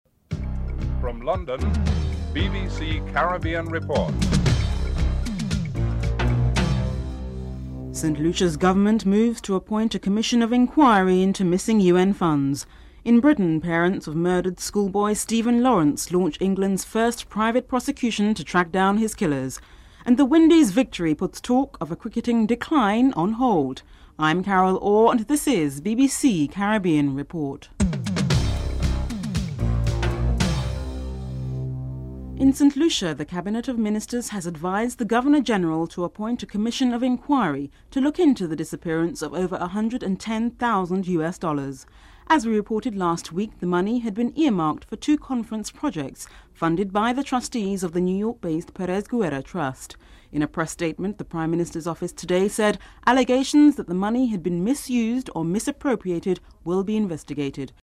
The British Broadcasting Corporation
Headlines